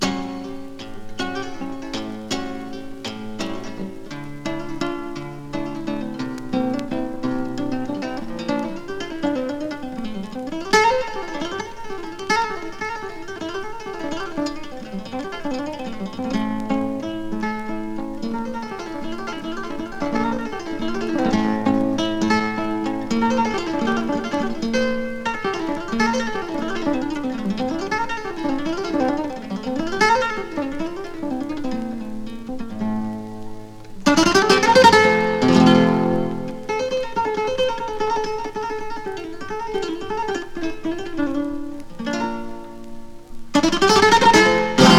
フラメンコスタイルと言えば彼と思える演奏曲の数々、抑揚の効いた超絶技巧で繰り出される音は強烈の一言。
World, Flamenco　USA　12inchレコード　33rpm　Stereo